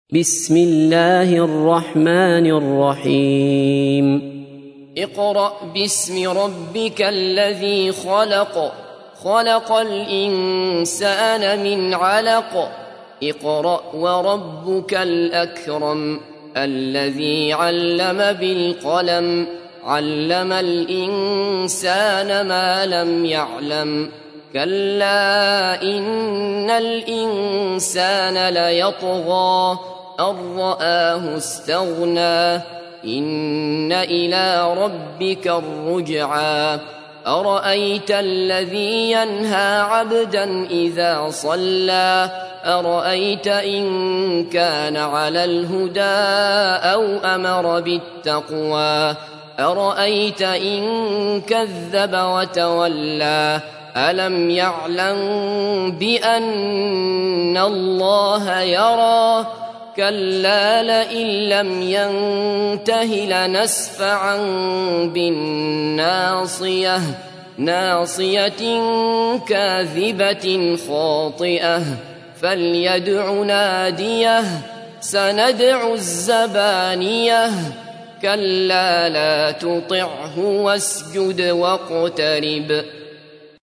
تحميل : 96. سورة العلق / القارئ عبد الله بصفر / القرآن الكريم / موقع يا حسين